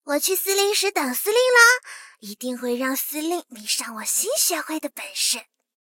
SU-76改二誓约语音.OGG